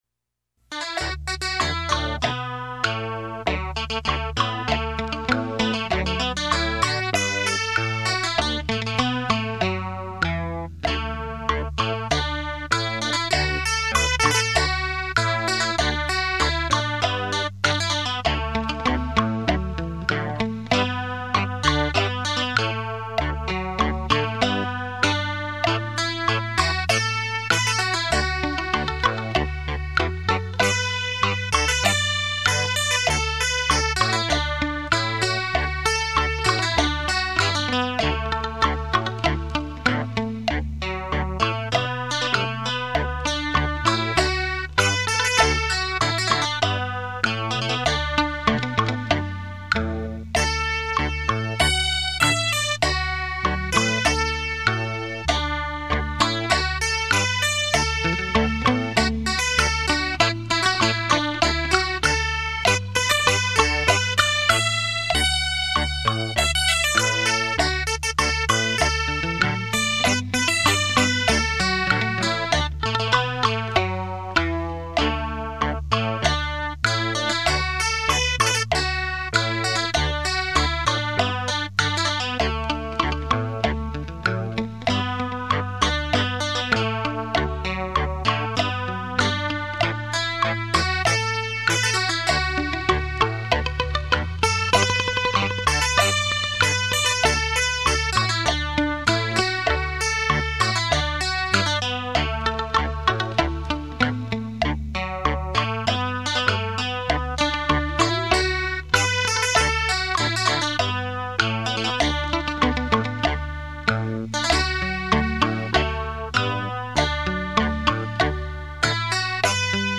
立体音声 环绕效果
100%绝佳的听觉享受度 100%声历身超级震撼度 100%立体声雷射音效